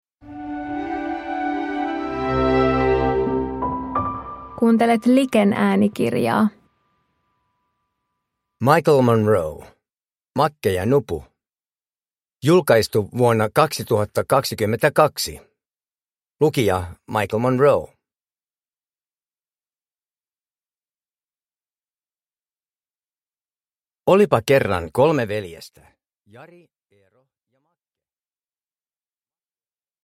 Makke ja Nupu – Ljudbok – Laddas ner
Uppläsare: Michael Monroe